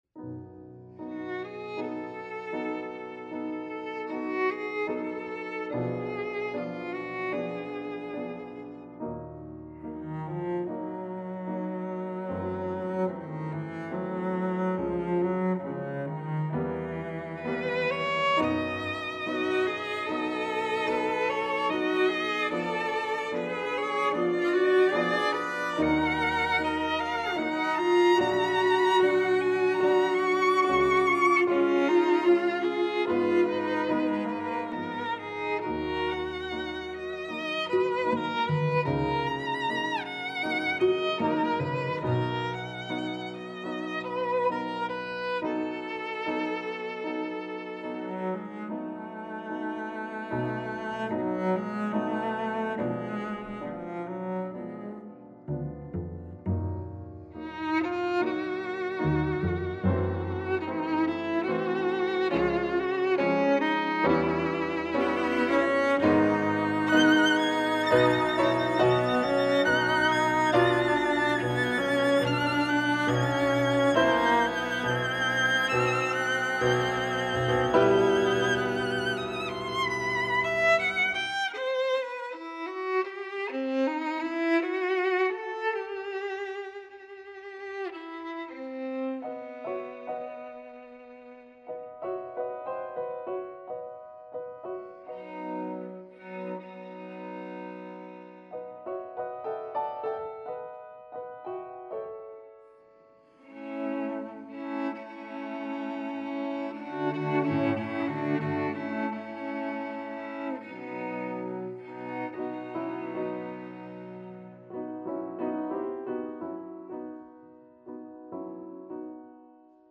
Voicing: String Trio